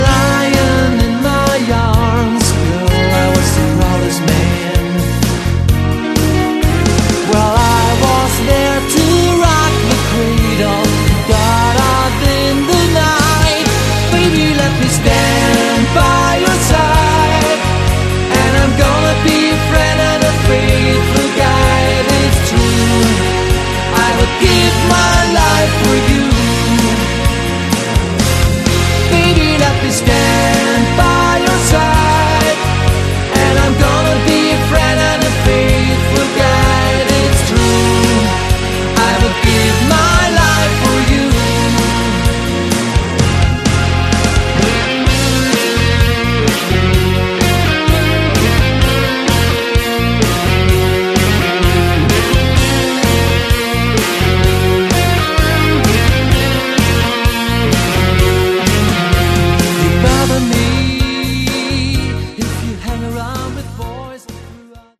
Category: AOR
vocals
guitars
bass
drums
keyboards